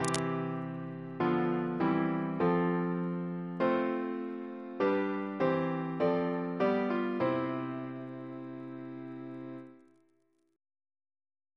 Single chant in C Composer: Sir John Stainer (1840-1901), Organist of St. Paul's Cathedral Reference psalters: ACB: 6; ACP: 76; H1940: 653; H1982: S18; RSCM: 206